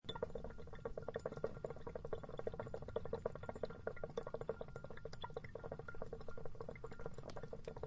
shisha_bubbling.ogg